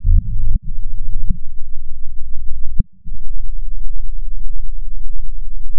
Amiga 8-bit Sampled Voice
Organ.mp3